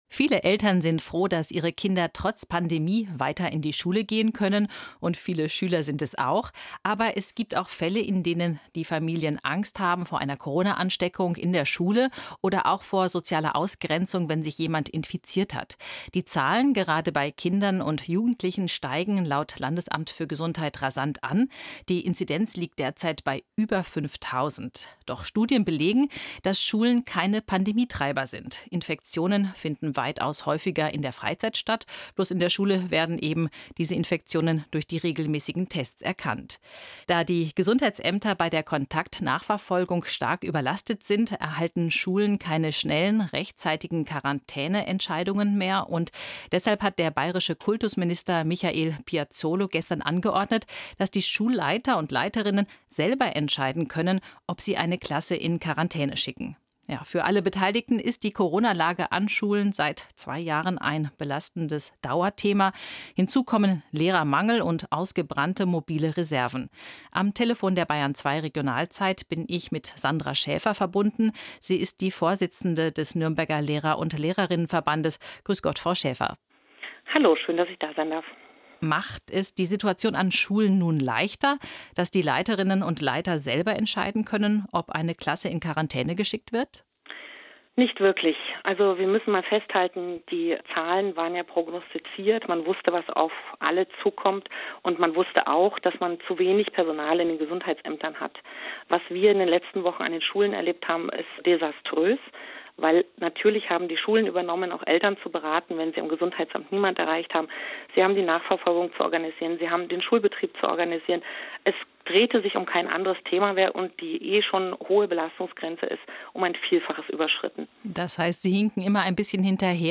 BR2 Radiobeitrag